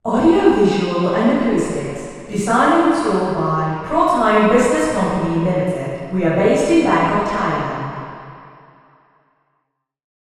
Below are auralizations for the four test positions in the study.
Receiver 03 Female Talker